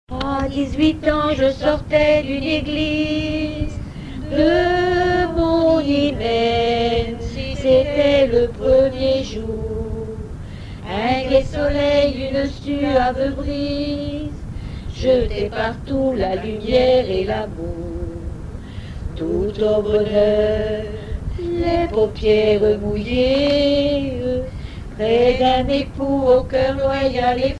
Genre strophique
Témoignages et chansons
Catégorie Pièce musicale inédite